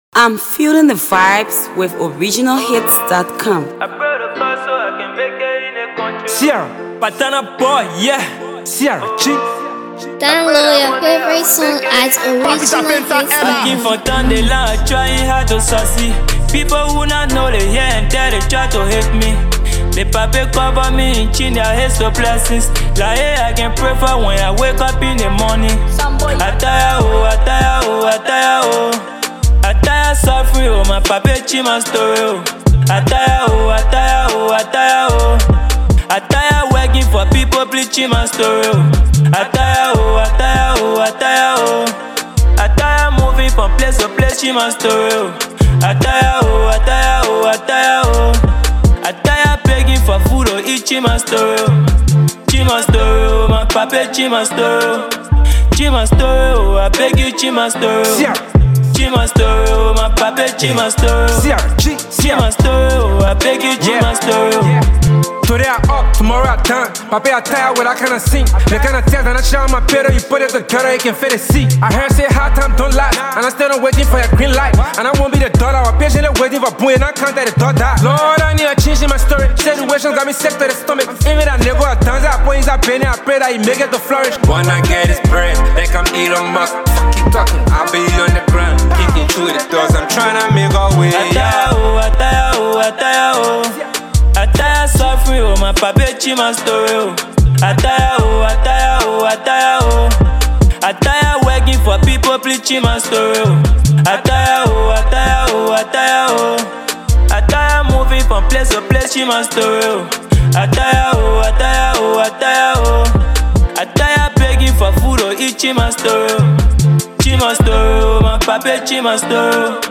trap rapper
blends lyrical depth with street-certified vibes